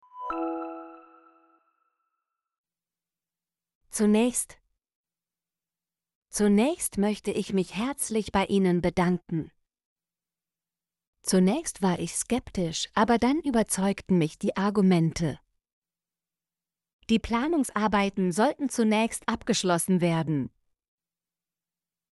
zunächst - Example Sentences & Pronunciation, German Frequency List